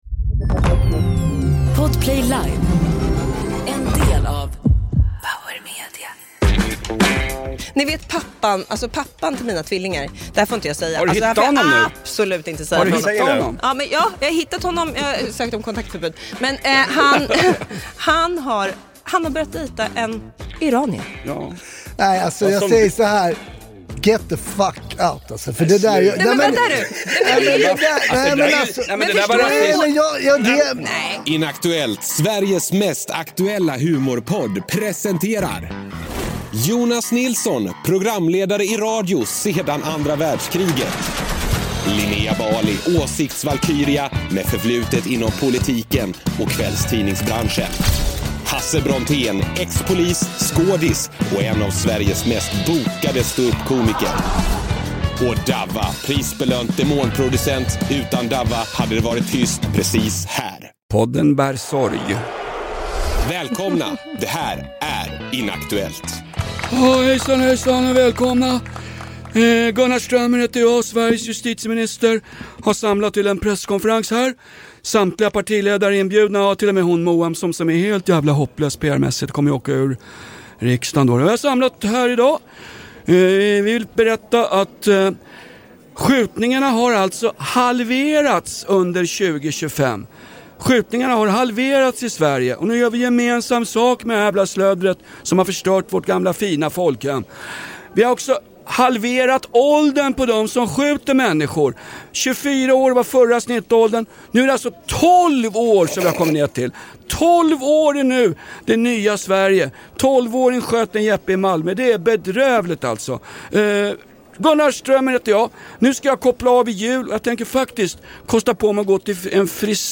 Podden Inaktuellt - Sveriges mest aktuella humorpodd! Förbered dig på saftiga och smarta analyser av vad som händer i vår omvärld, självklart med en stor skopa satir, sluggerslag och låga träffar.